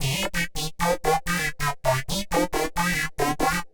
Index of /musicradar/uk-garage-samples/128bpm Lines n Loops/Synths